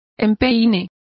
Complete with pronunciation of the translation of vamps.